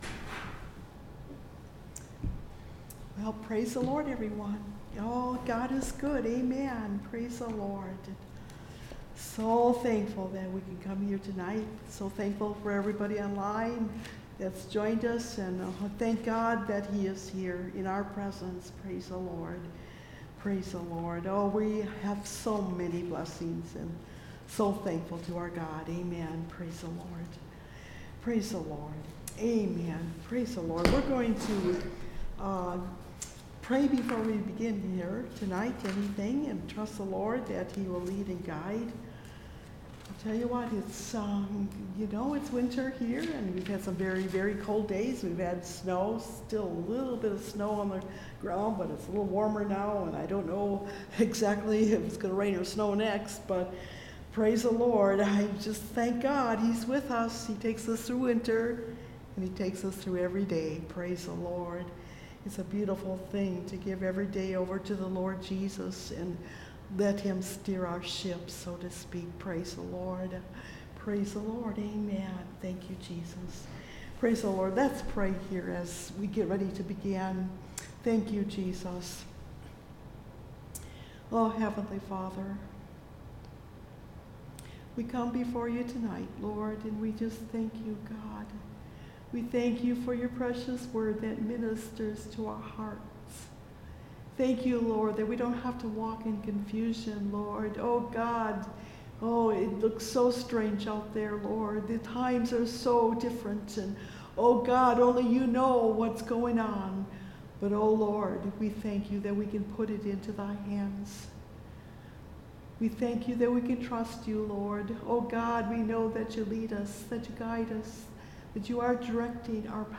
Revive Us Again (Message Audio) – Last Trumpet Ministries – Truth Tabernacle – Sermon Library